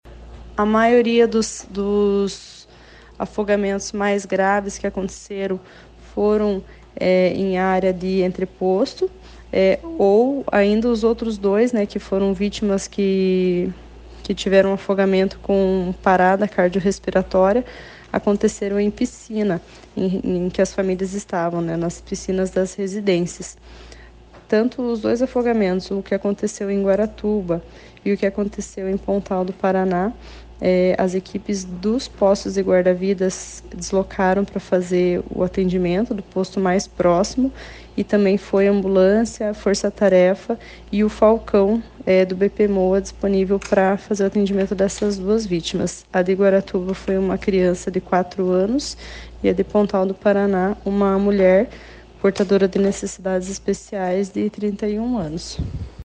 Sonora da capitã do Corpo de Bombeiros-Militar do Paraná